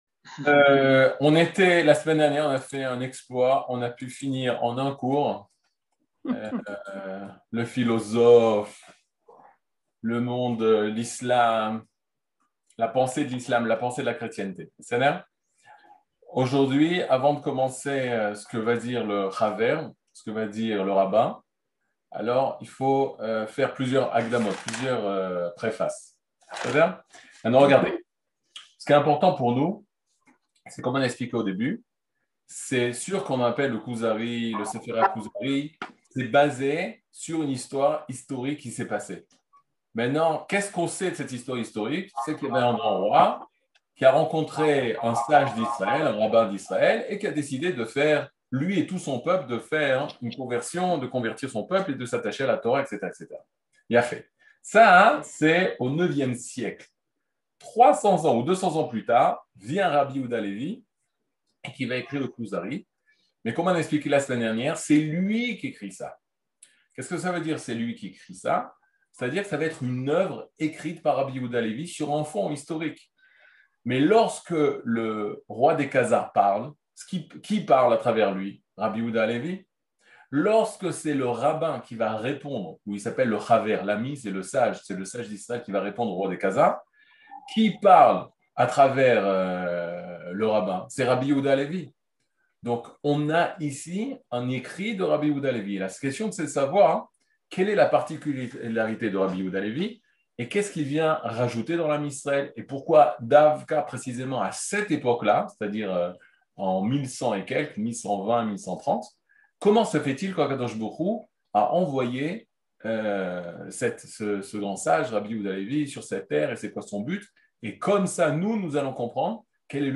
Catégorie Le livre du Kuzari partie 26 00:47:10 Le livre du Kuzari partie 26 cours du 16 mai 2022 47MIN Télécharger AUDIO MP3 (43.18 Mo) Télécharger VIDEO MP4 (112.69 Mo) TAGS : Mini-cours Voir aussi ?